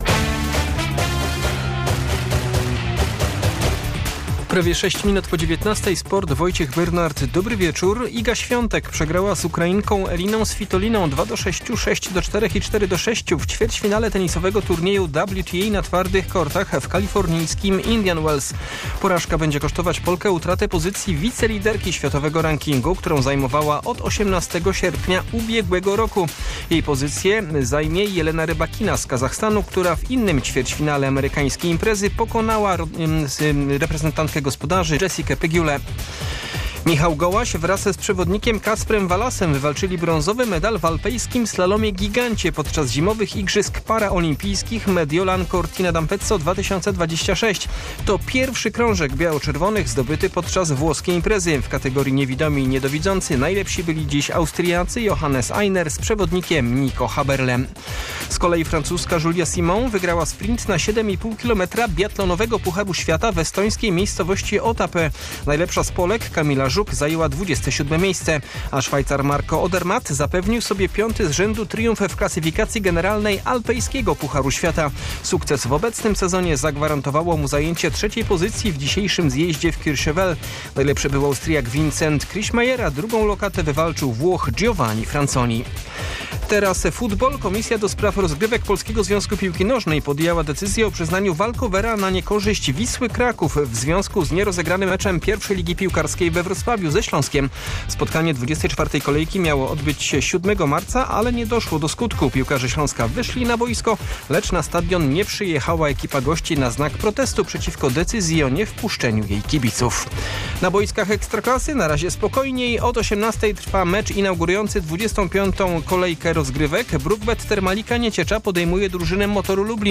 13.03.2026 SERWIS SPORTOWY GODZ. 19:05